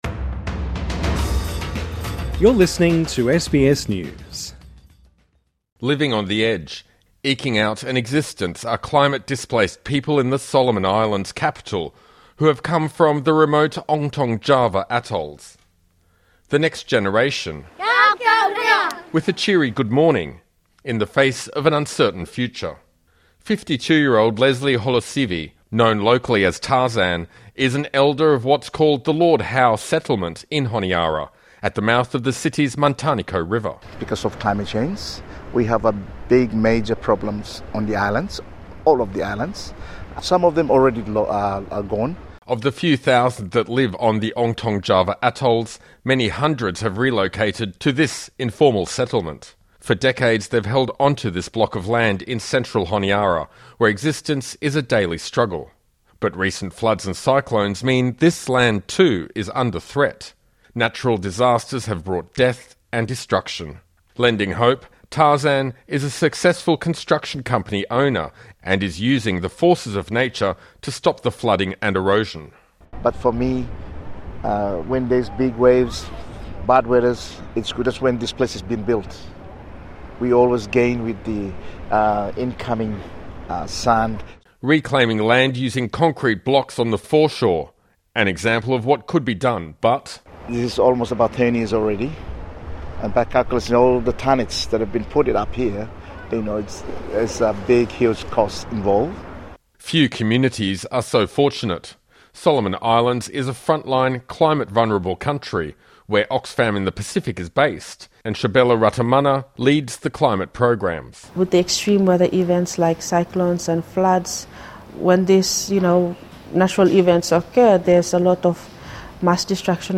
(Sound effect: KIDS YELLING) ..with a cheery “good morning”, in the face of an uncertain future.